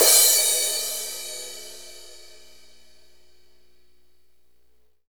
Index of /90_sSampleCDs/Northstar - Drumscapes Roland/DRM_AC Lite Jazz/CYM_A_C Cymbalsx